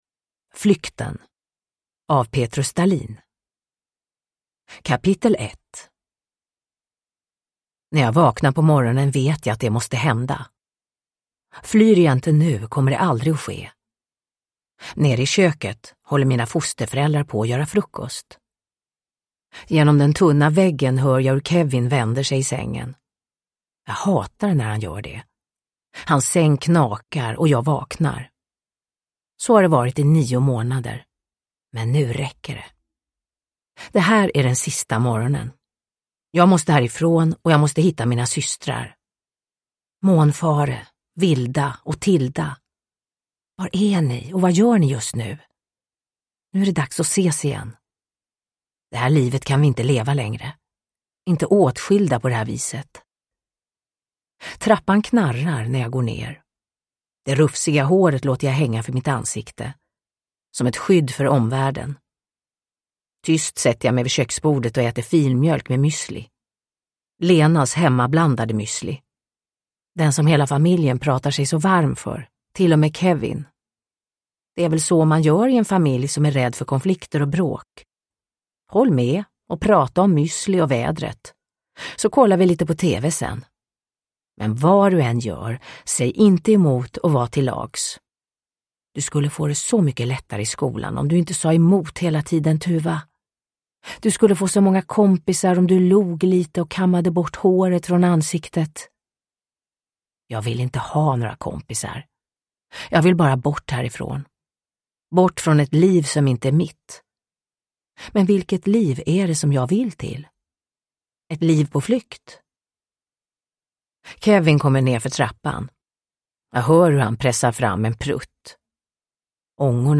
Flykten – Ljudbok – Laddas ner
Uppläsare: Marie Richardson